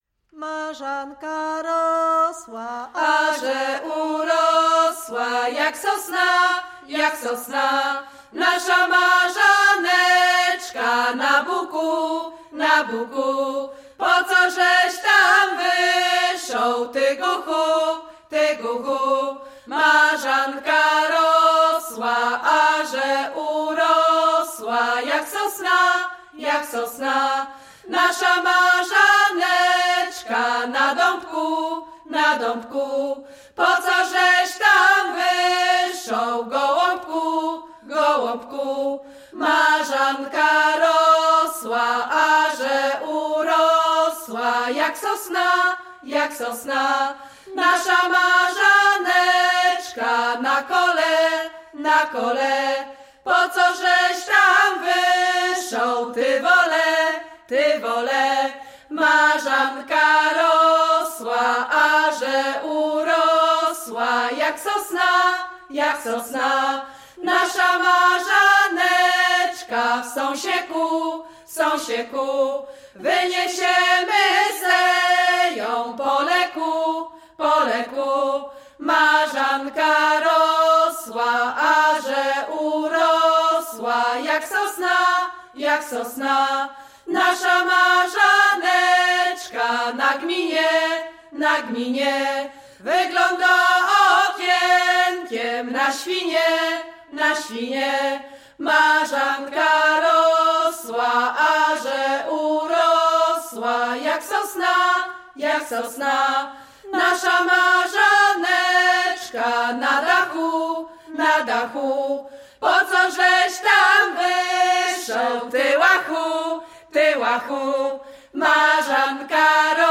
Niezłe Ziółka
Śląsk Opolski
Marzanna
gaik kolędowanie wiosenne maik marzanna wiosna wiosenne